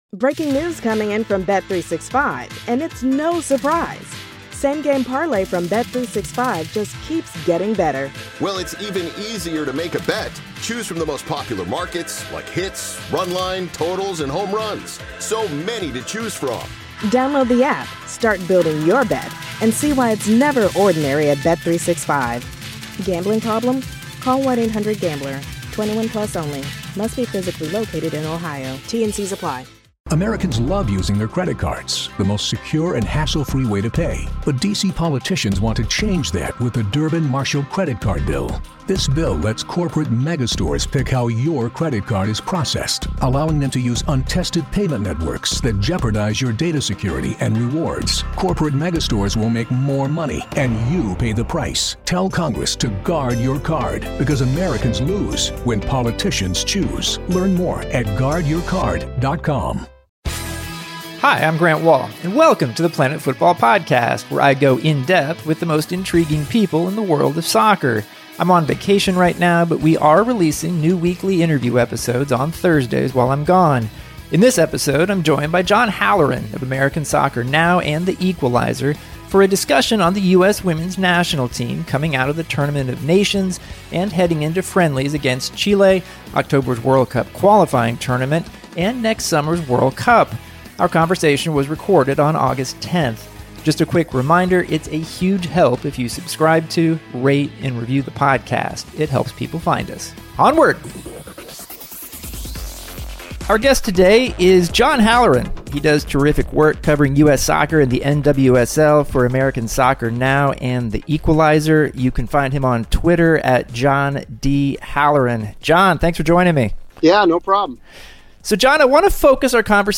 Grant interviews